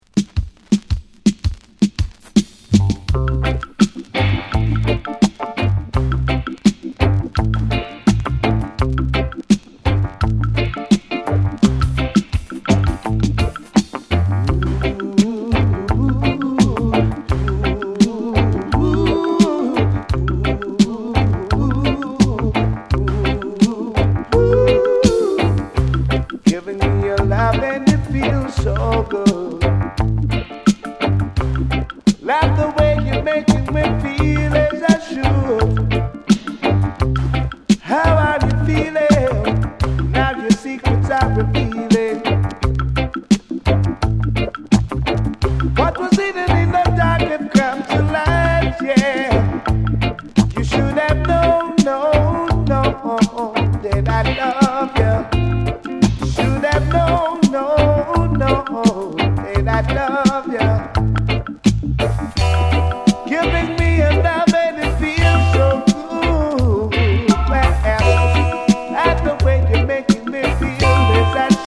ジャマイカン・ラヴァーズとダンスホールの質感のリズムで心地良く揺られる名曲。